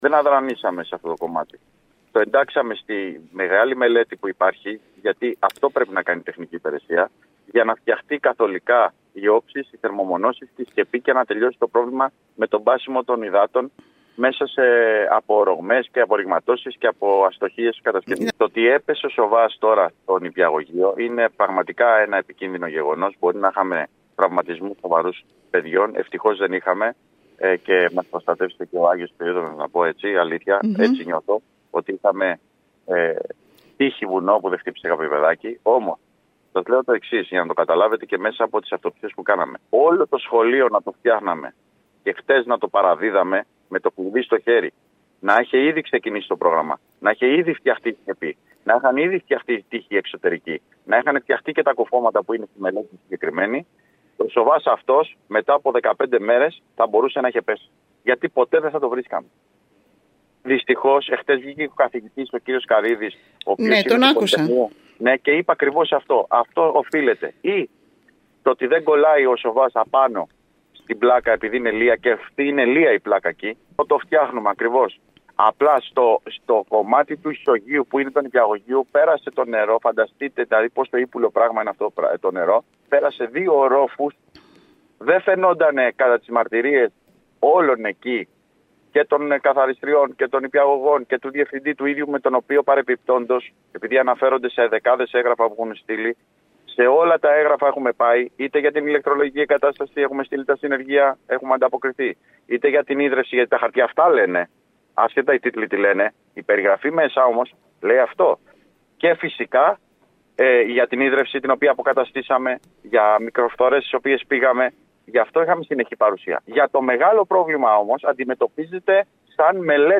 Μιλώντας στην ΕΡΤ Κέρκυρας ο αντιδήμαρχος τεχνικών υπηρεσιών Νίκος Καλόγερος επεσήμανε ότι  η διάβρωση που προκάλεσε την πτώση δεν ήταν δυνατόν να προβλεφθεί ενώ ήδη έχει ξεκινήσει η αφαίρεση του σοβά και το επόμενο διάστημα θα αποφασιστεί αν η οροφή θα καλυφθεί με γυψοσανίδα ή με άλλο υλικό.